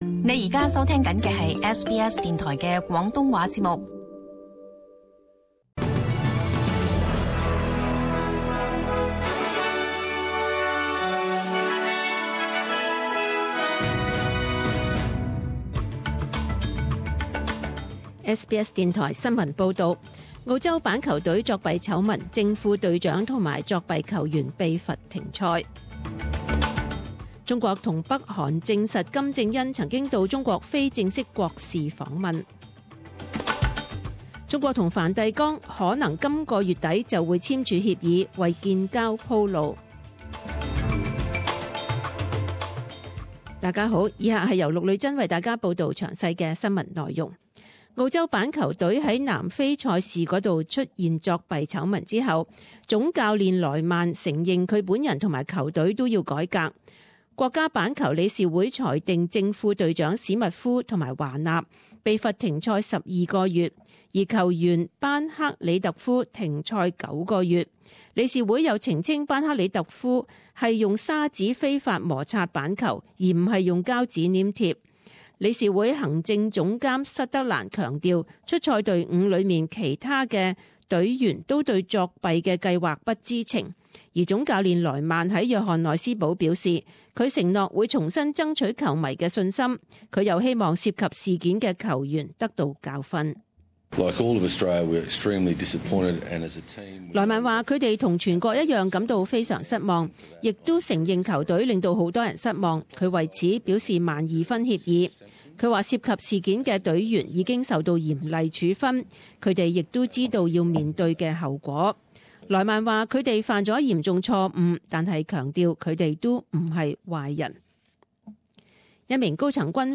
SBS中文新闻 （三月二十九日）
请收听本台为大家准备的详尽早晨新闻。